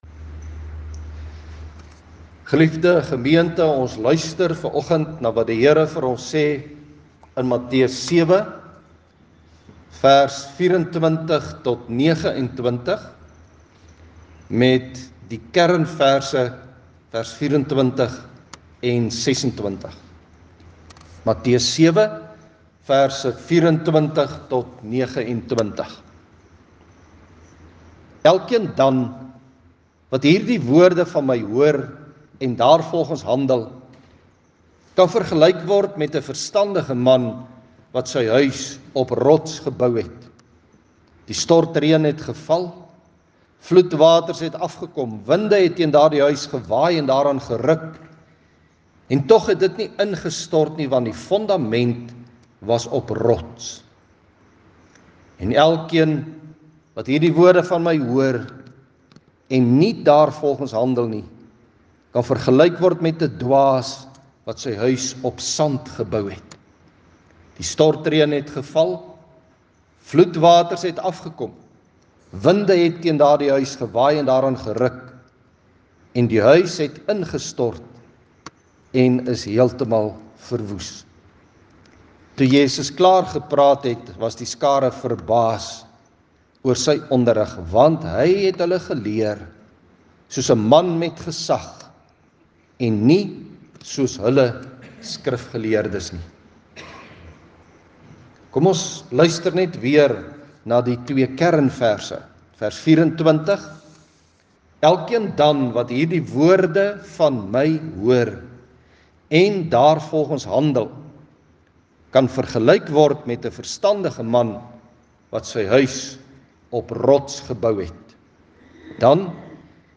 Die Here praat met ons deur sy Woord en deur Nagmaal en Hy leer ons vanoggend om ons lewe op Christus te bou en hoe belangrik dit is dat ons ons lewe op Hom sal bou.